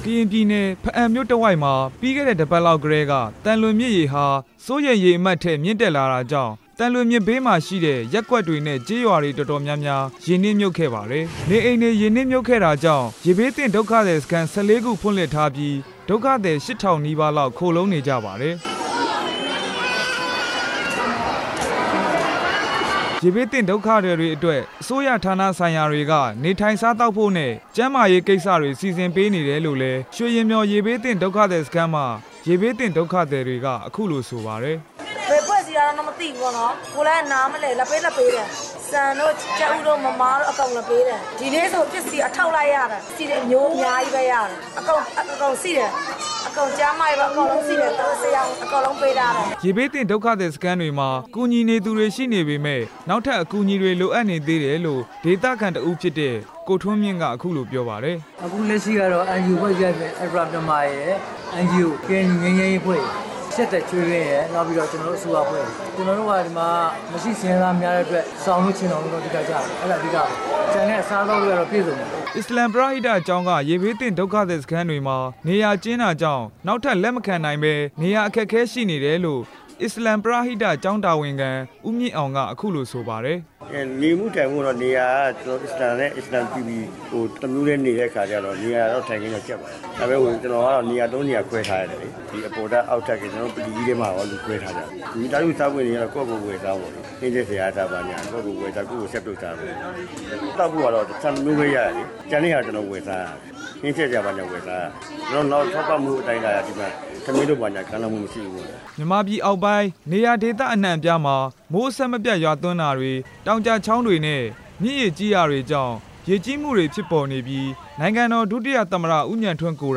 ဘားအံမြို့ရေဘေးအကြောင်း တင်ပြချက်